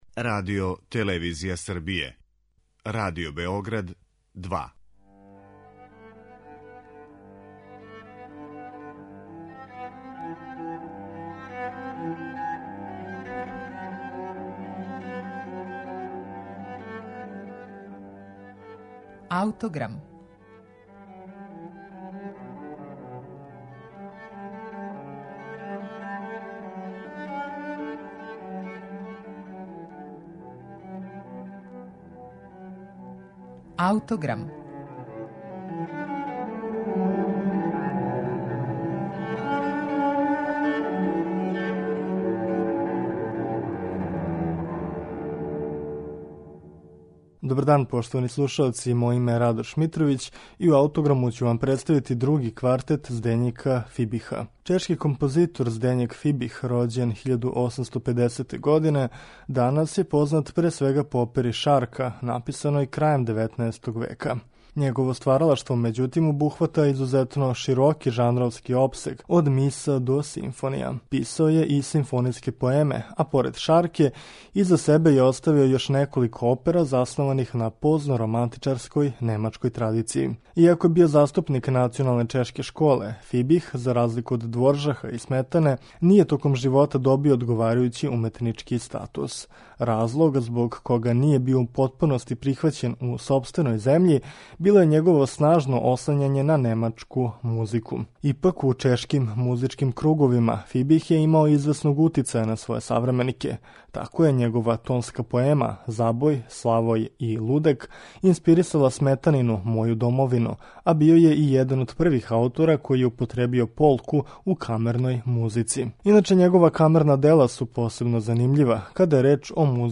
утицаји немачког романтизма и чешког фолклора